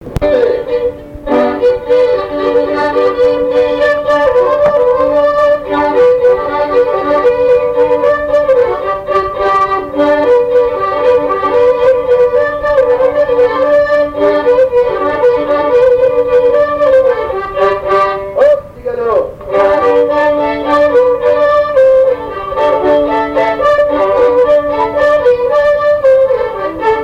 danse : quadrille : galop
Pièce musicale inédite